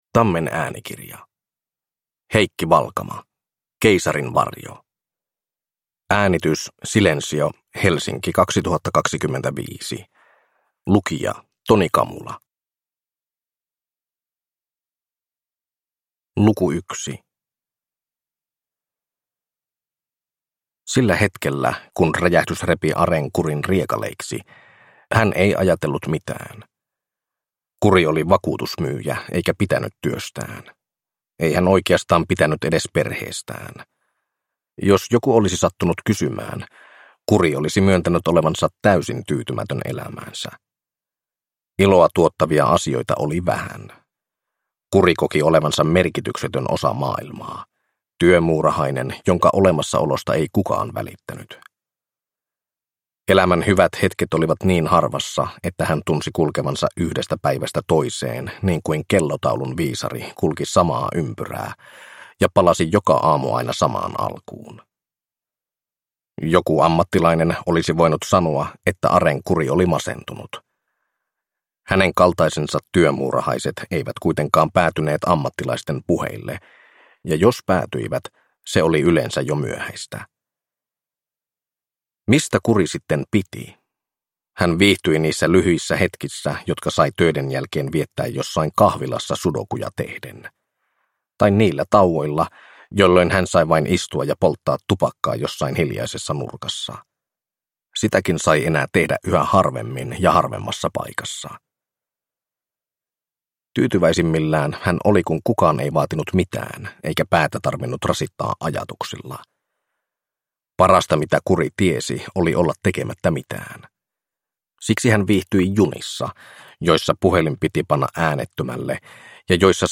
Keisarin varjo (ljudbok) av Heikki Valkama